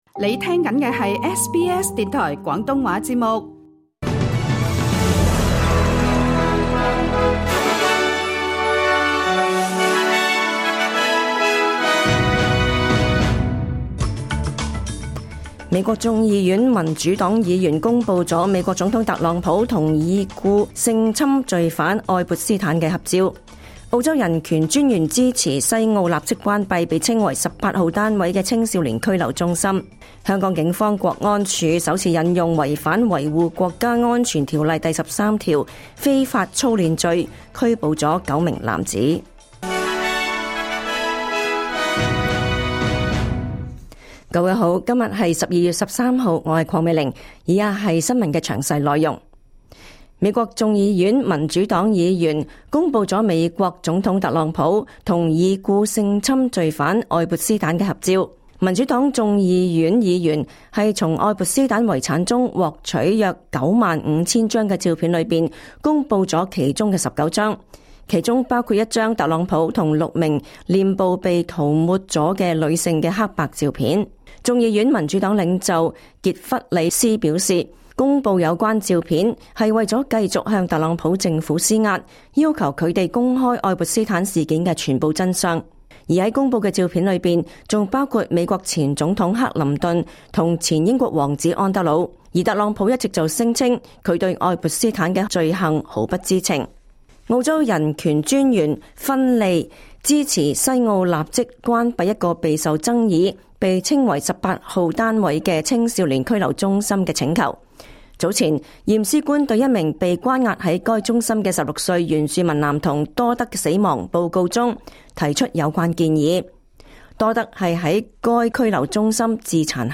2025 年 12 月 13 日 SBS 廣東話節目詳盡早晨新聞報道。